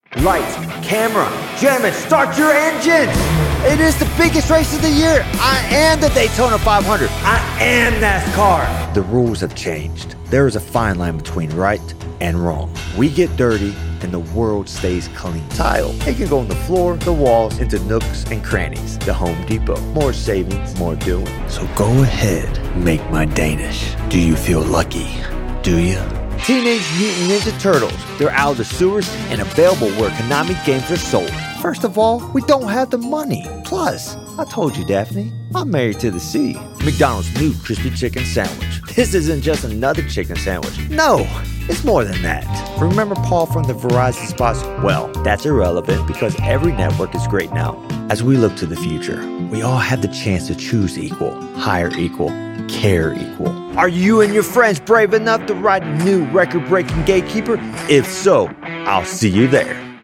Professional Voice Actor / Actor
Resume and Voice Demo